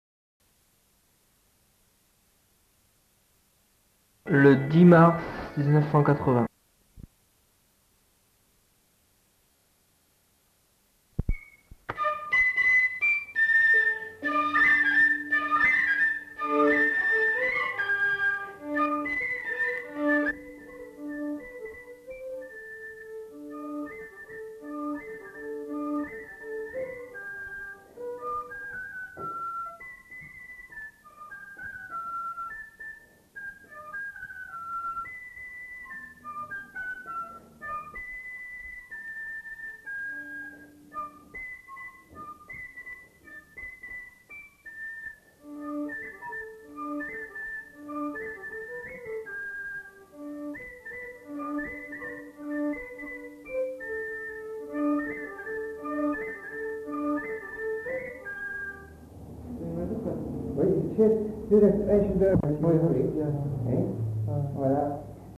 Aire culturelle : Bazadais
Lieu : Bazas
Genre : morceau instrumental
Instrument de musique : fifre ; violon
Danse : varsovienne